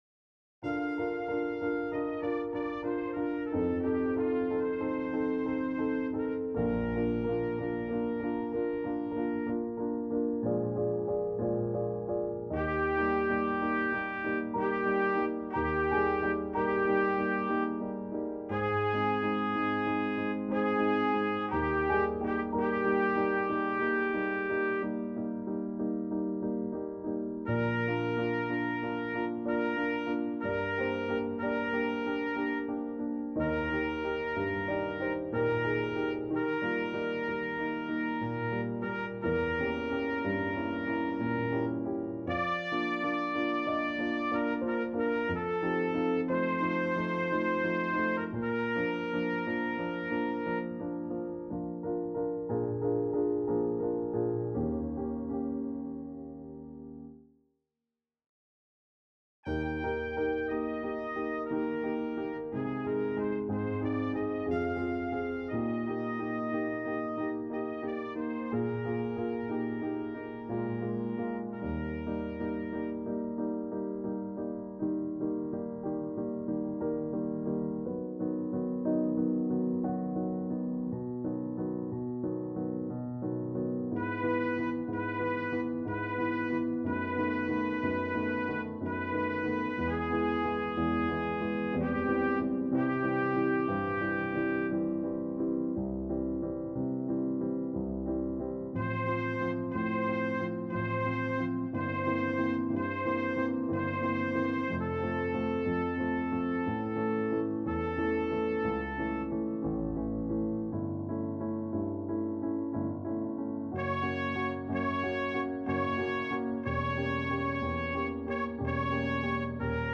장로성가단 연습음원